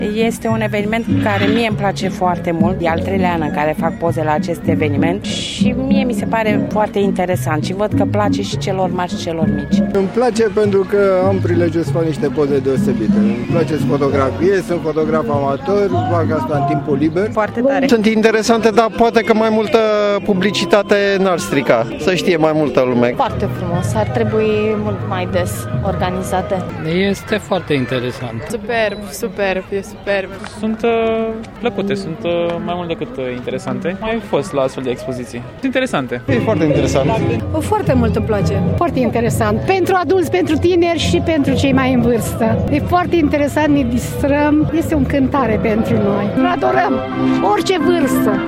Un reportaj
vox-vivante-30-mai.mp3